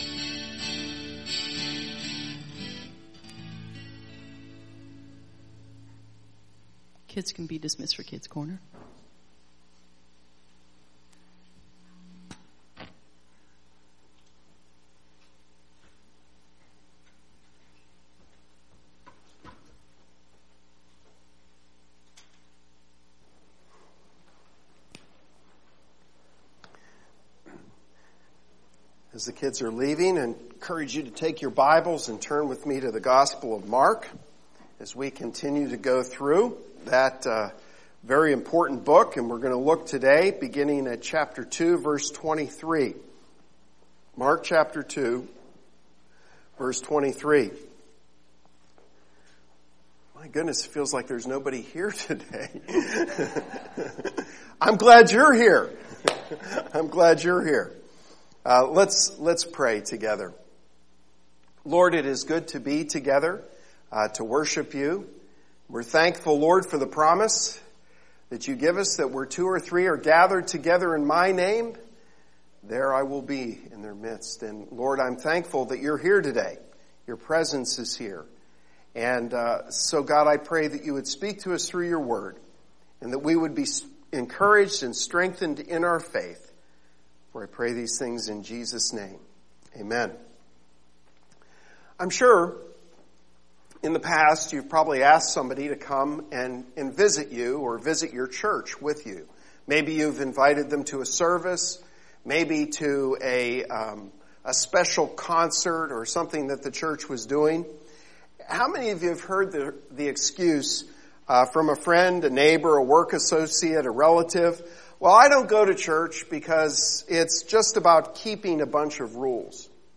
Sermon April 3 Mark 2:23-28